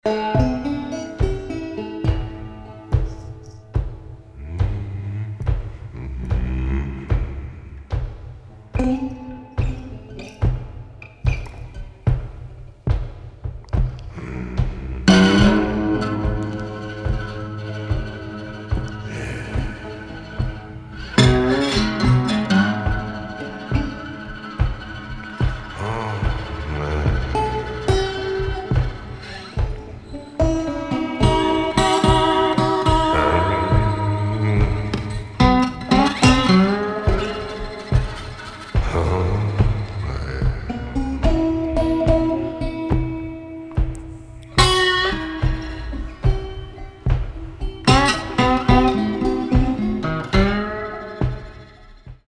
Tinged with blues and Cajun stylings